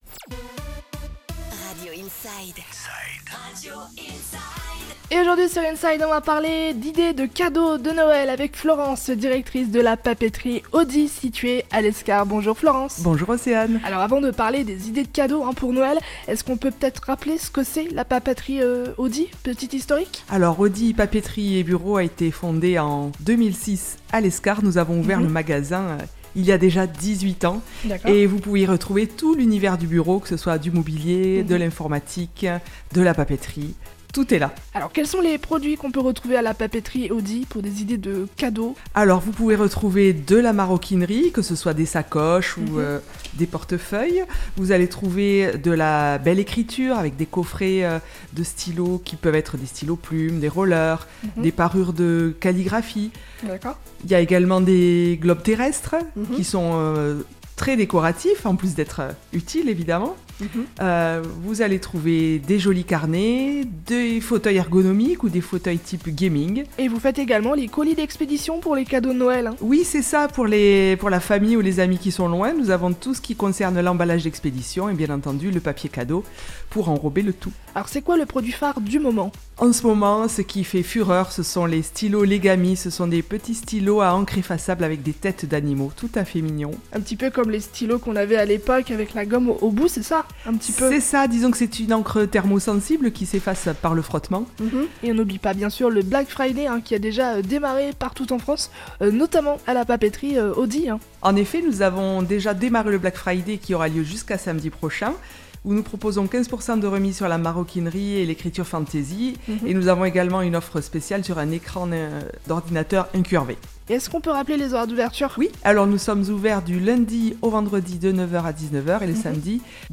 INSIDE : Réécoutez les flash infos et les différentes chroniques de votre radio⬦